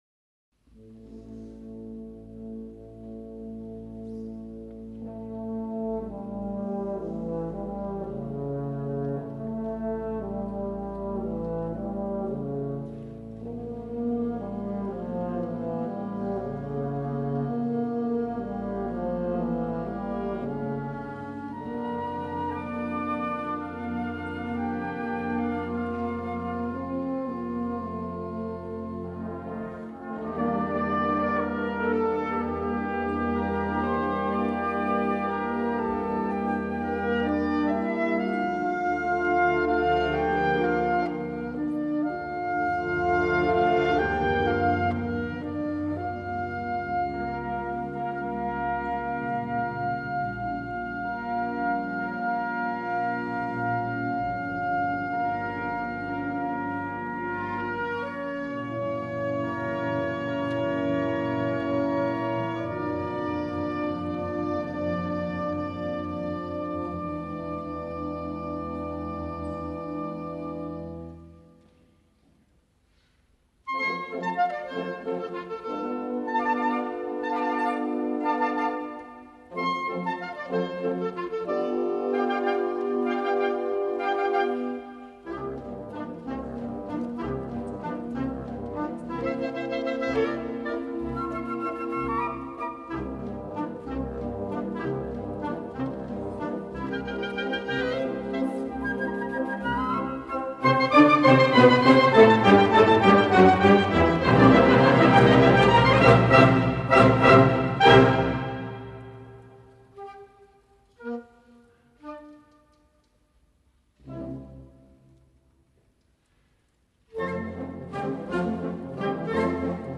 Gattung: Walzer
A4 Besetzung: Blasorchester PDF